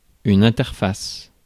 Ääntäminen
Ääntäminen France: IPA: [ɛ̃.tɛʁ.fas] Haettu sana löytyi näillä lähdekielillä: ranska Käännös Konteksti Substantiivit 1. interface kemia Suku: f .